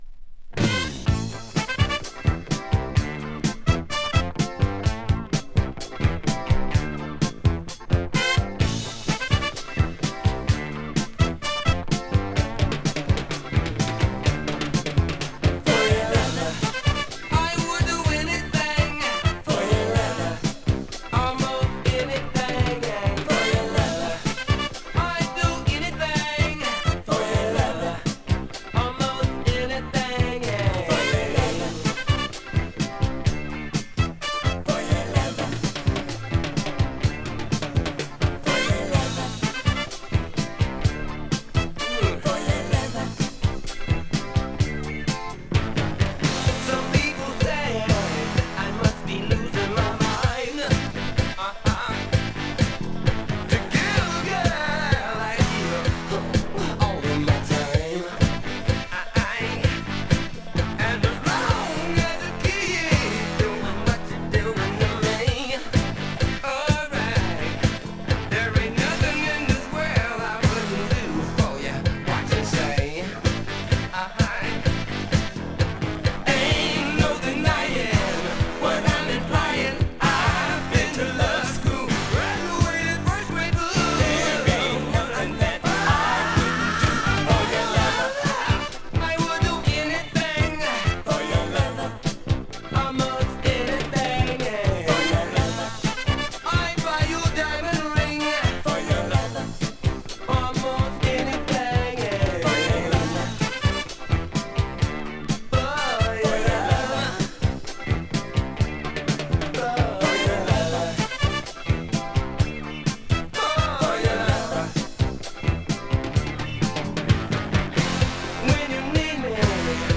[DISCO] [BOOGIE]
Philly-sounding boogie track
disco funk